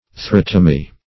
Search Result for " urethrotomy" : The Collaborative International Dictionary of English v.0.48: Urethrotomy \U`re*throt"o*my\, n. [Urethra + Gr.